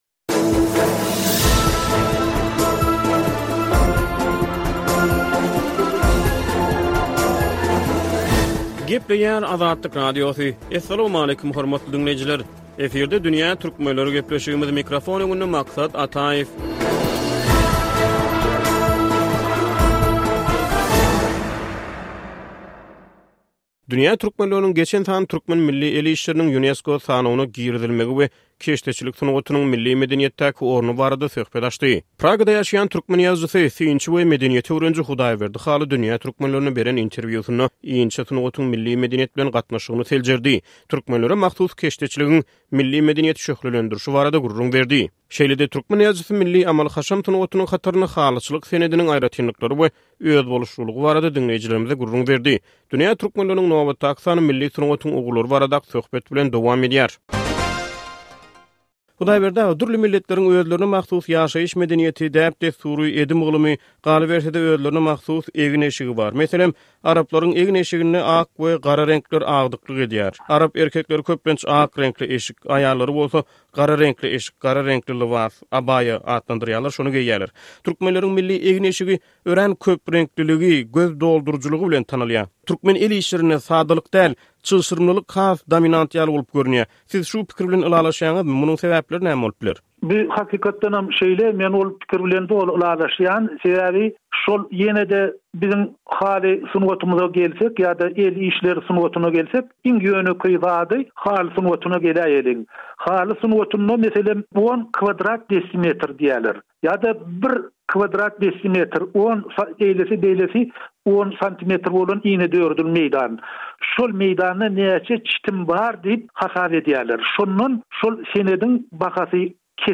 Birleşen Milletler Guramasynyň Bilim, ylym we medeniýet boýunça edarasy (UNESCO) dekabryň başynda türkmen keşdeçilik sungatyny Adamzadyň maddy däl medeni mirasynyň sanawyna girizdi. Dünýä Türkmenleriniň nobatdaky sany keşdeçilik sungatynyň milli medeniýetdäki orny baradaky söhbet bilen dowam edýär.